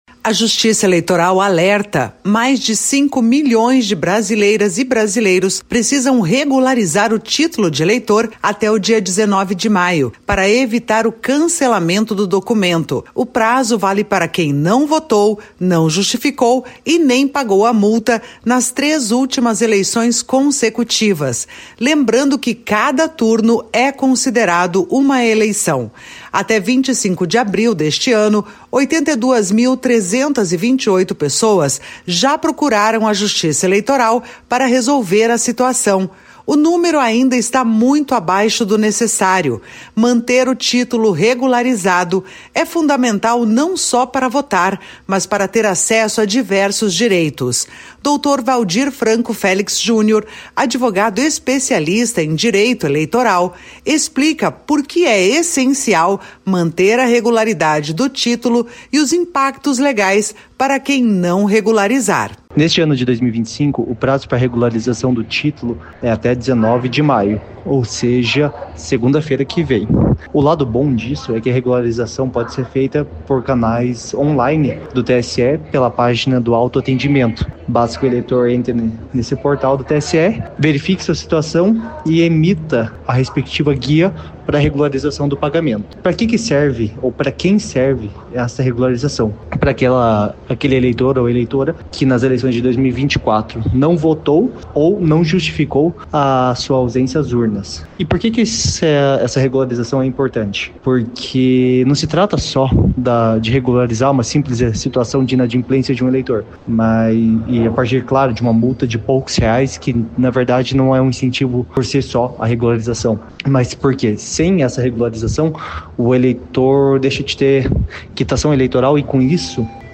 advogado especialista em Direito Eleitoral, explica por que é essencial manter a regularidade do título e os impactos legais para quem não regularizar.